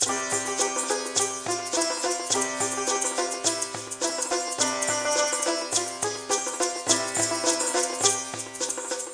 00628_Sound_Static.mp3